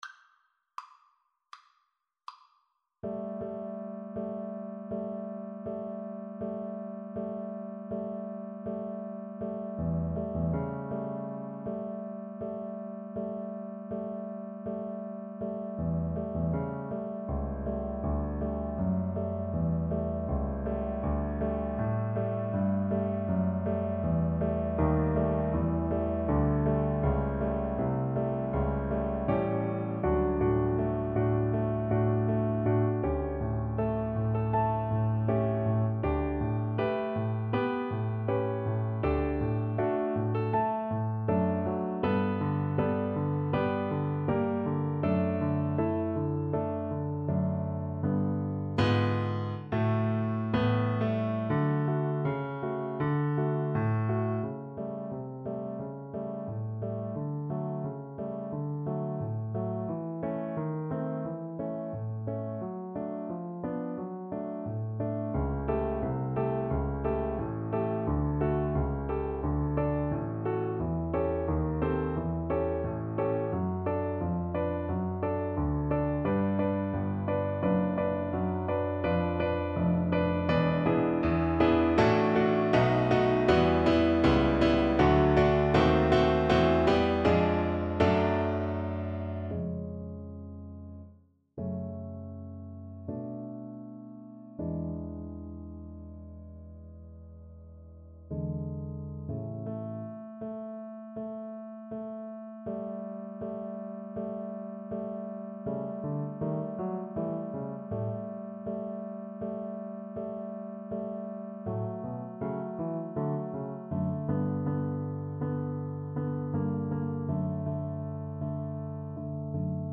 D major (Sounding Pitch) (View more D major Music for Violin )
4/4 (View more 4/4 Music)
Andante espressivo